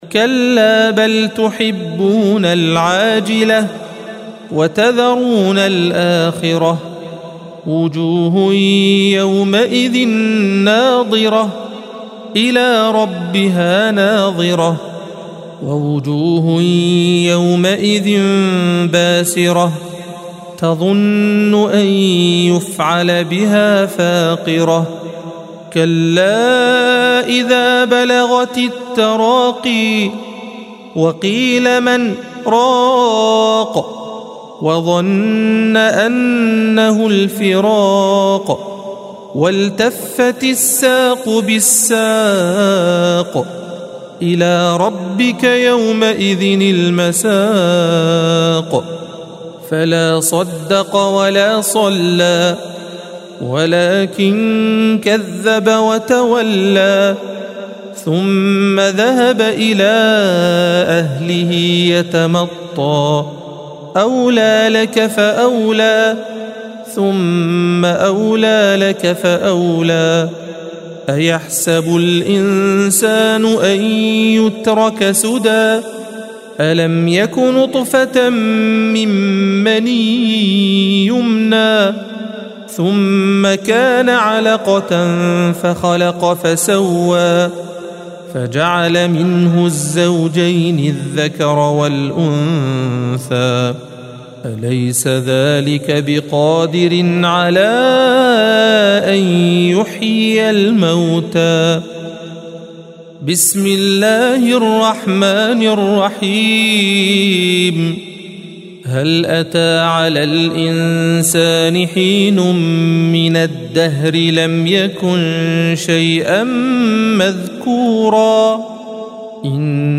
الصفحة 578 - القارئ